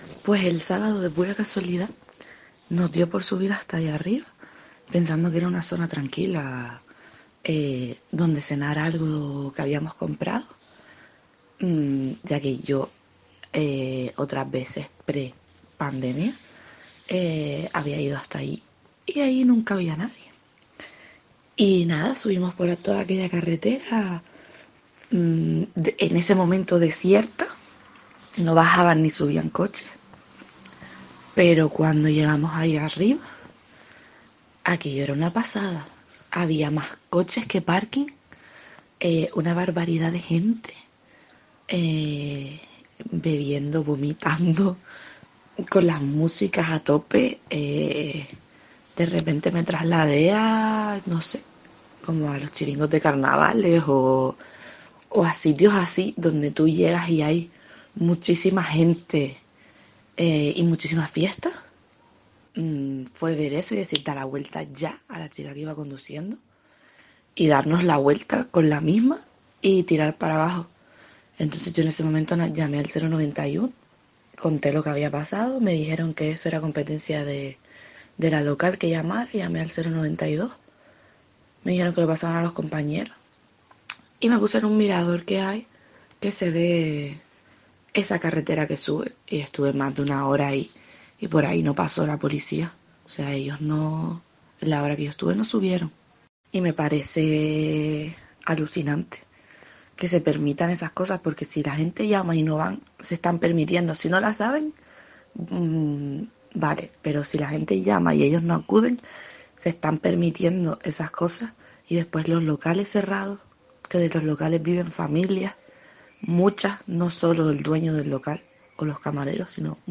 Una vecina de San Bartolomé de Tirajana denuncia los botellones